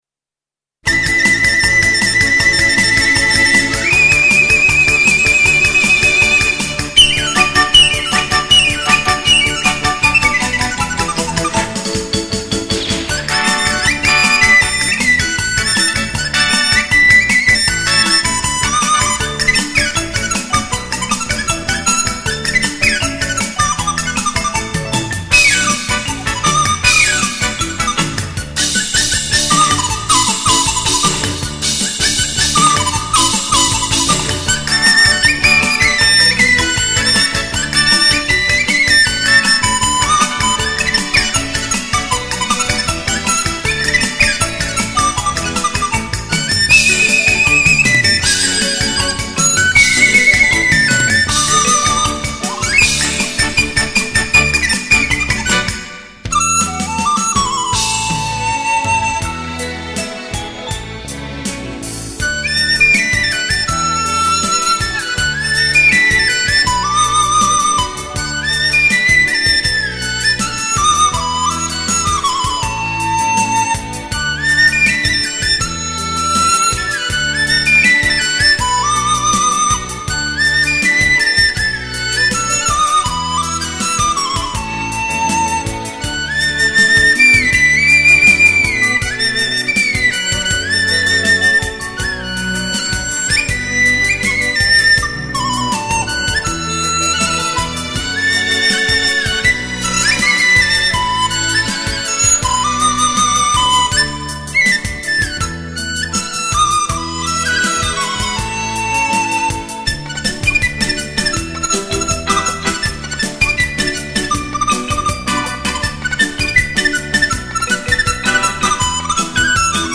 笛子、葫芦丝、洞萧、巴乌独奏
中国民乐精品系列。
为低音质MP3